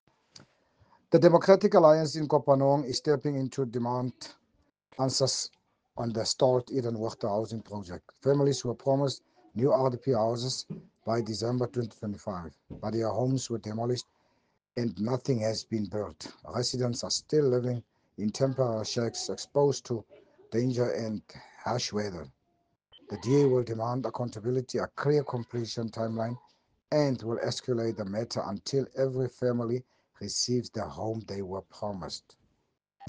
Afrikaans soundbites by Cllr Richard van Wyk and